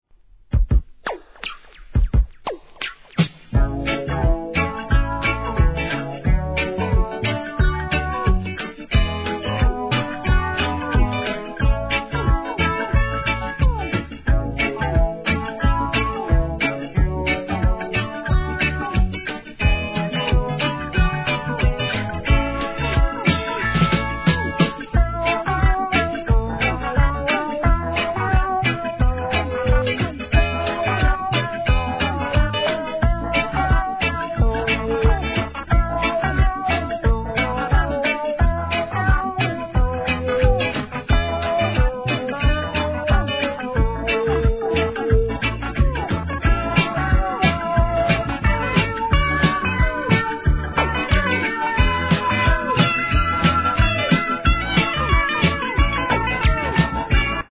¥ 1,100 税込 関連カテゴリ REGGAE 店舗 ただいま品切れ中です お気に入りに追加 国内盤